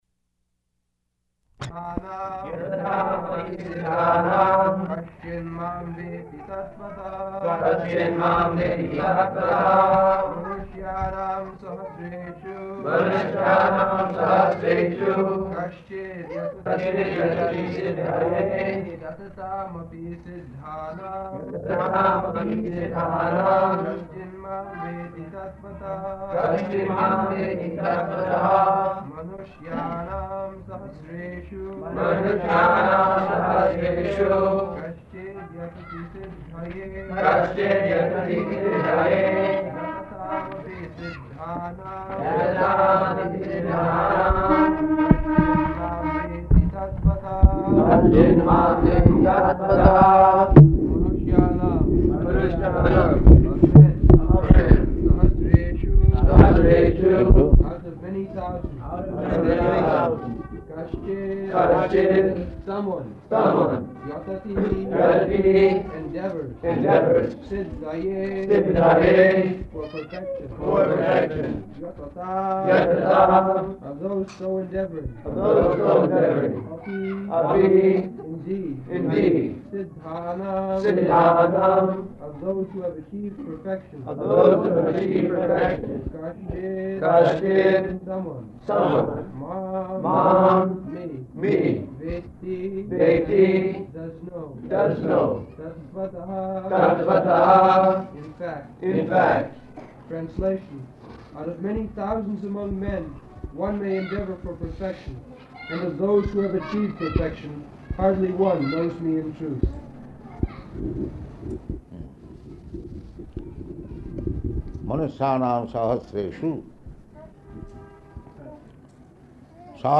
February 18th 1974 Location: Bombay Audio file
[devotees repeat]